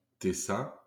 ^ Italian: Repubblica e Cantone Ticino, informally Canton Ticino [kanˈton tiˈtʃiːno]; Lombard: Cantón Teṡín [kanˈtoŋ teˈziŋ]; German: Kanton Tessin [ˈkantɔn tɛˈsiːn] ; French: Canton du Tessin [kɑ̃tɔ̃ dy tɛsɛ̃]